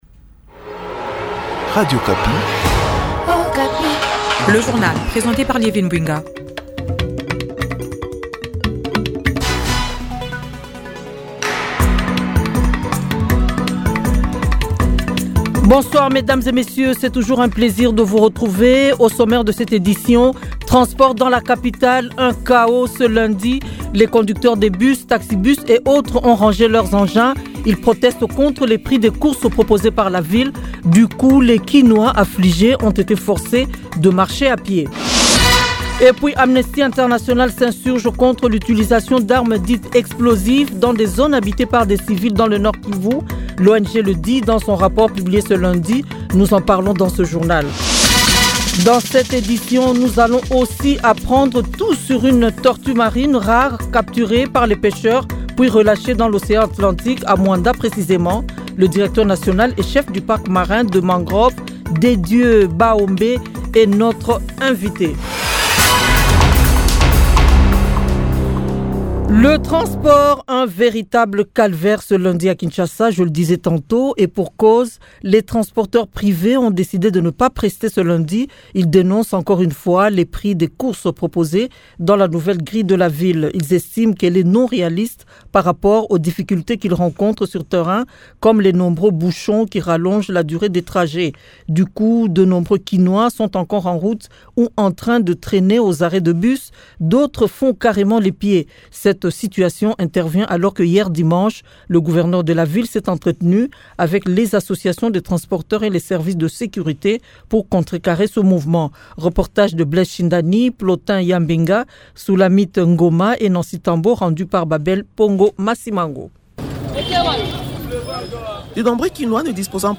Journal 18h lundi 20 janvier 2025